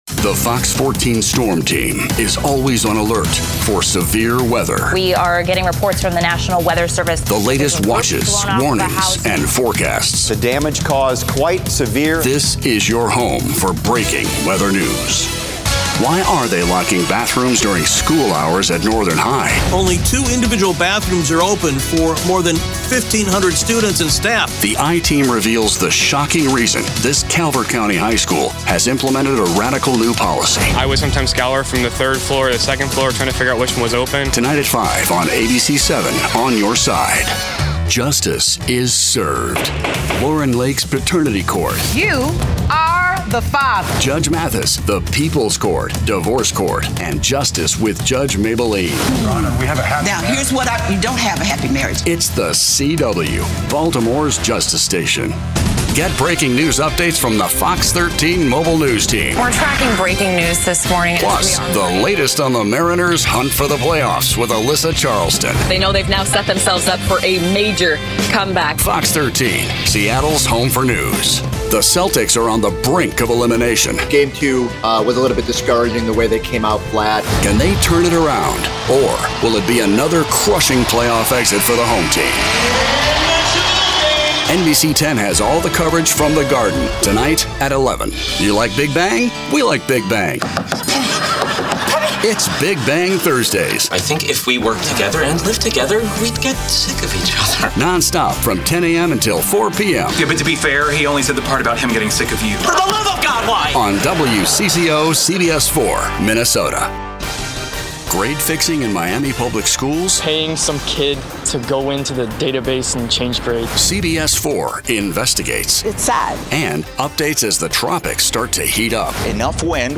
Male
Adult (30-50), Older Sound (50+)
Radio / TV Imaging
Tv Affiliate Promo
0506TV_Affiliate_Promo_Demo.mp3